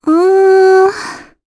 Cassandra-Vox-Deny_jp.wav